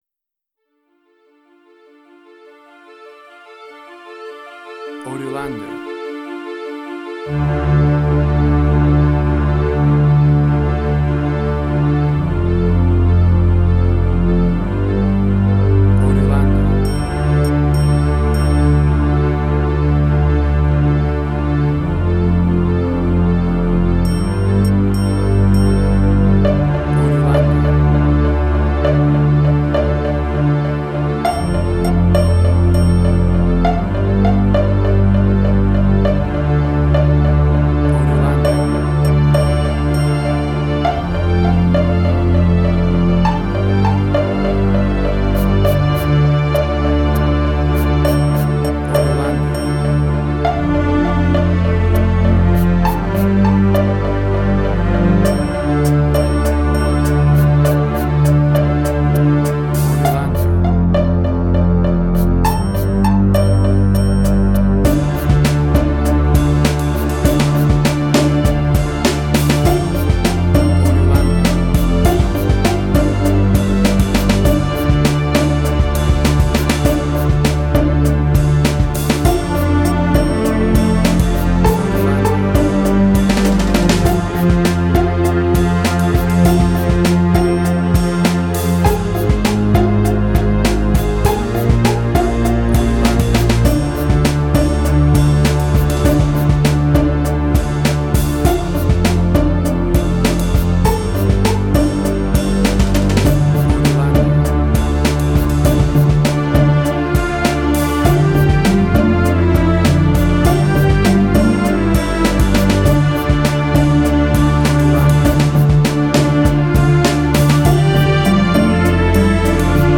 Tempo (BPM): 100